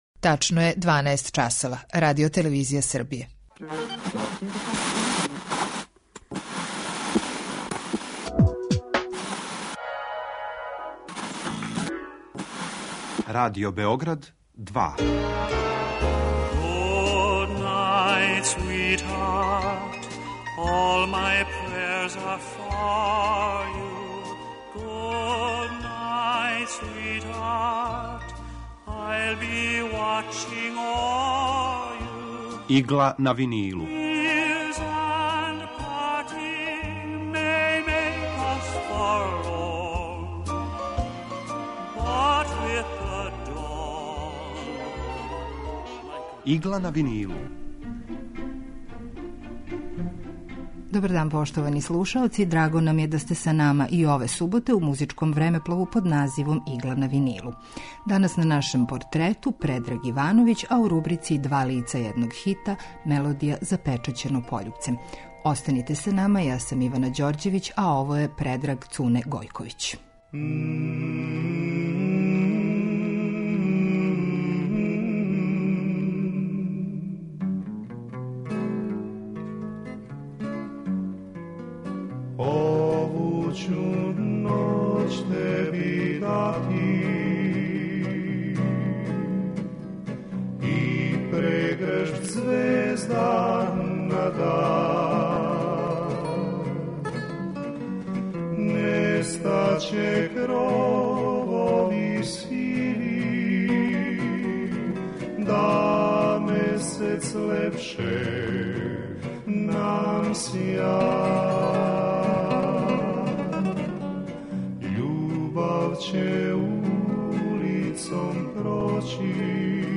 Емисија евергрин музике
У Игли на винилу представљамо одабране композиције евергрин музике од краја 40-их до краја 70-их година 20. века.